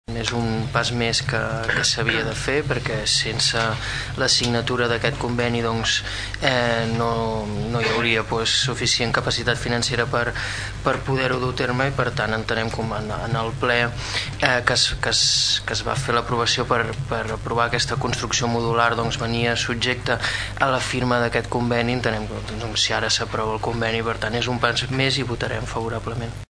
El regidor PP Xavier Martin va celebrar que finalment s’hagi desencallat aquest conveni.